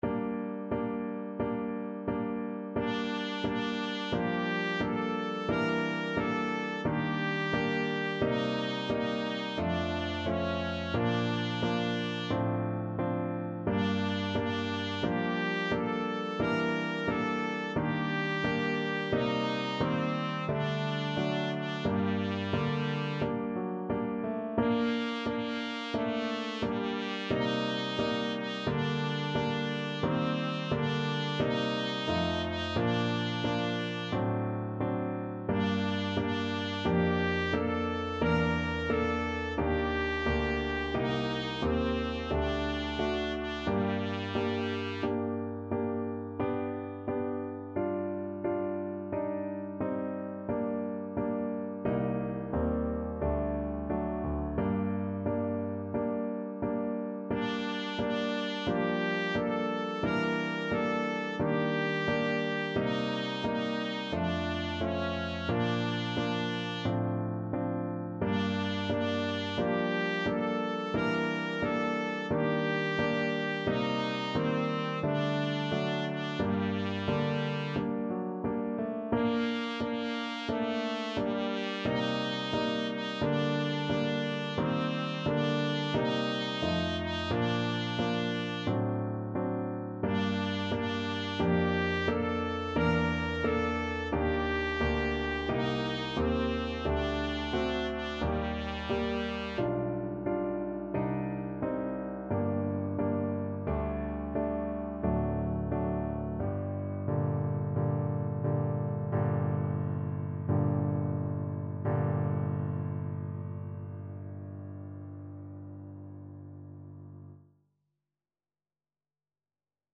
Classical Trad. Kojo no Tsuki Trumpet version
G minor (Sounding Pitch) A minor (Trumpet in Bb) (View more G minor Music for Trumpet )
4/4 (View more 4/4 Music)
Andante =c.88
Classical (View more Classical Trumpet Music)